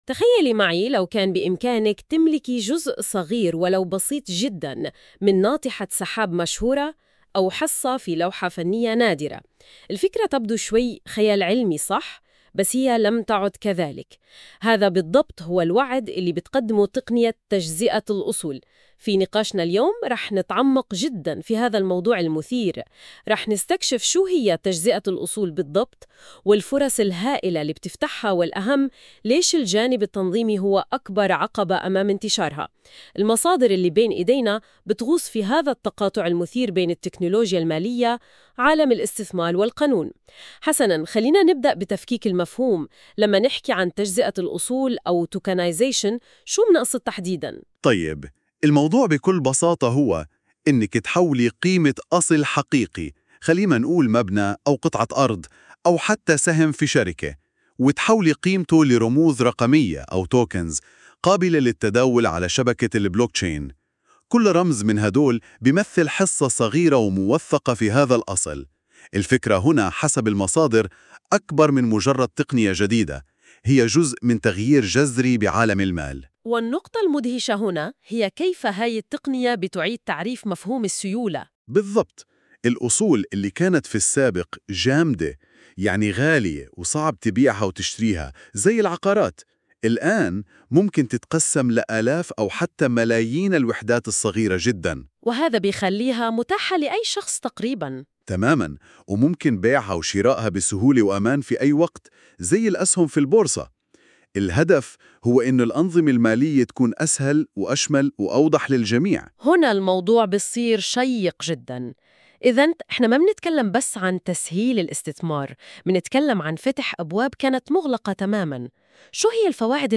يمكنك الاستماع إلى هذا المقال بدلاً من قراءته عبر المقطع الصوتي التالي👇